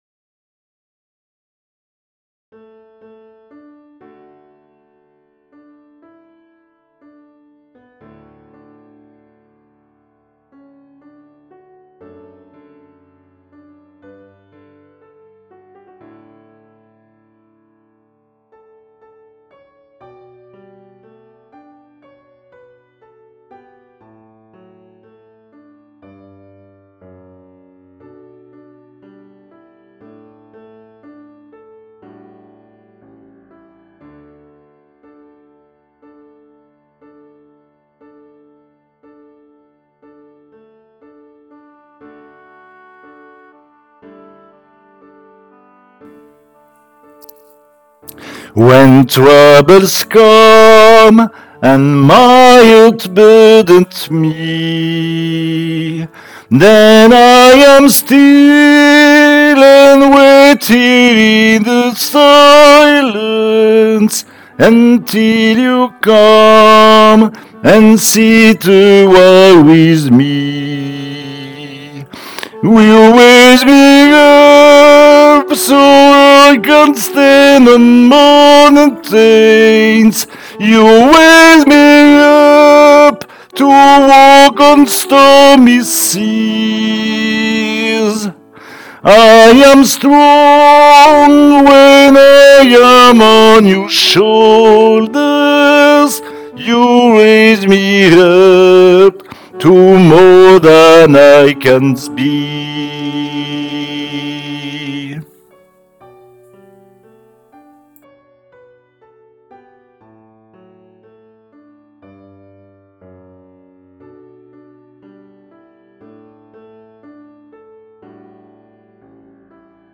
voix chantée